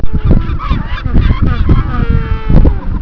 Penguins on a different beach
Isla Magdalena, near Punta Arenas, Chile